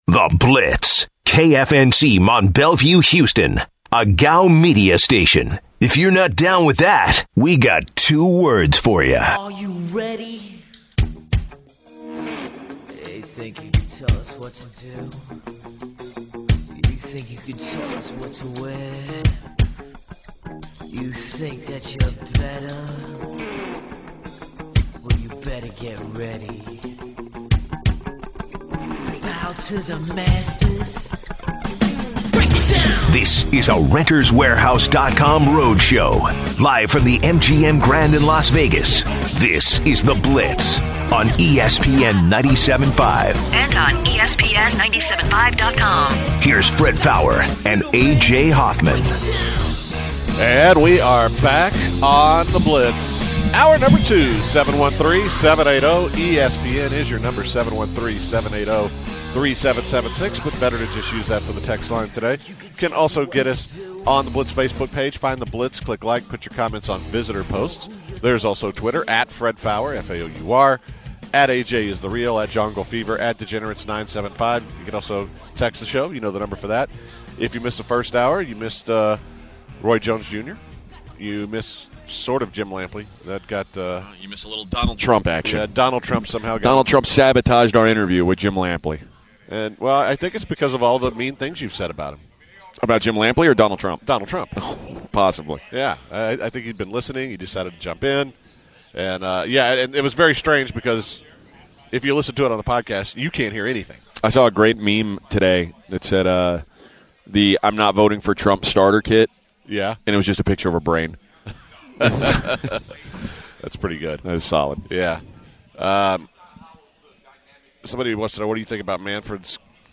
live from Las Vegas, Nevada.